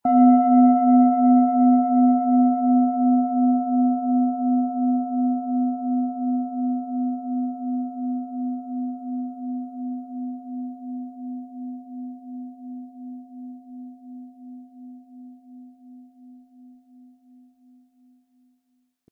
Planetenton
Delphin
Die Planetenklangschale Delfin ist handgefertigt aus Bronze.Die Klangschalen lassen wir von kleinen Manufakturen anfertigen.